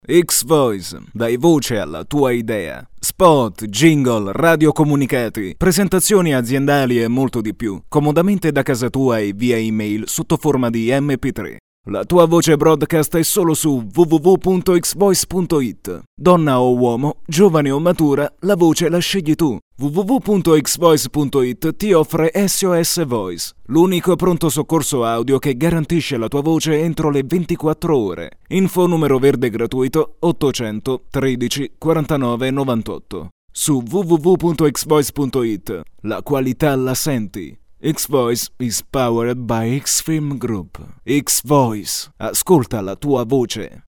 Sprechprobe: Werbung (Muttersprache):
Warm italian voice for documntary, e -learning, audioguide, audiobook etc.